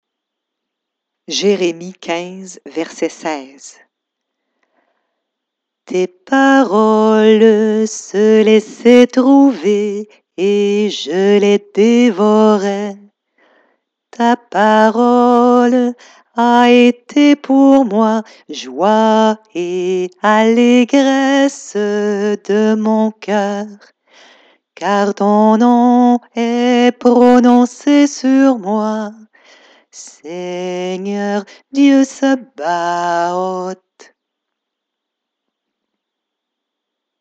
Versets chantés en récitatif
(chanté plus bas)   Verset du Cinquantenaire